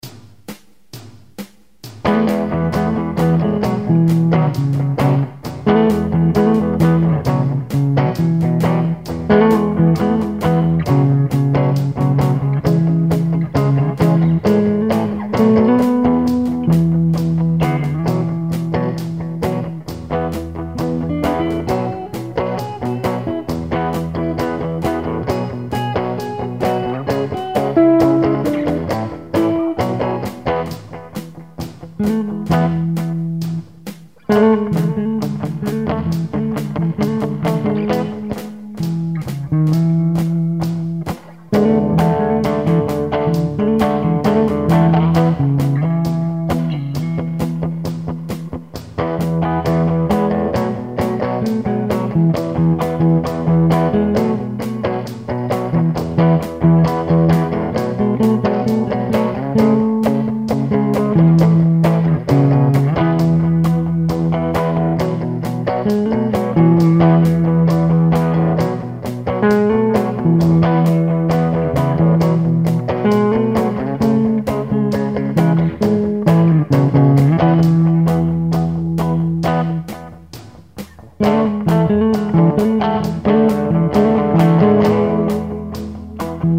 I found a tone for Dire Straits and.